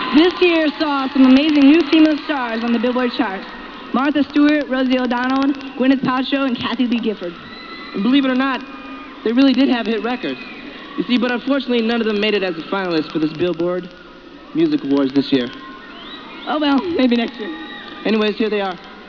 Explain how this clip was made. Sounds Recorded From TV Shows I apologize for the quality of the sounds as I was not able to directly line-in record them, so they are slightly fuzzy